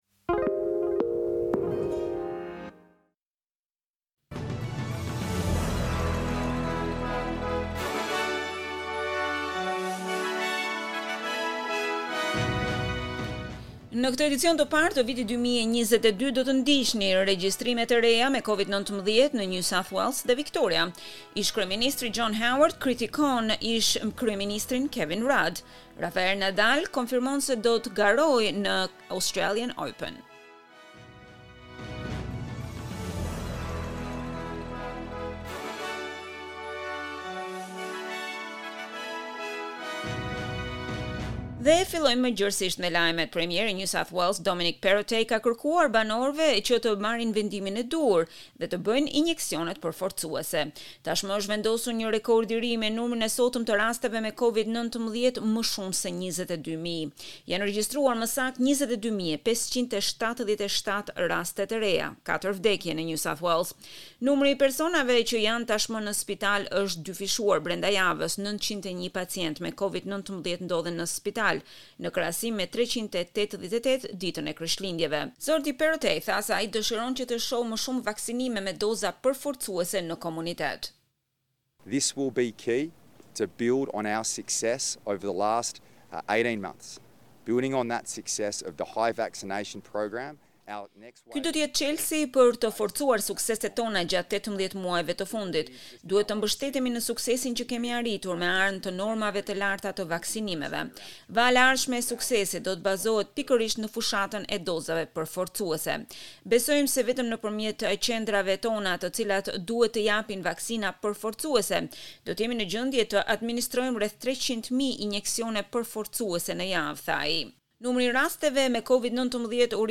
SBS News Bulletin - 1 January 2022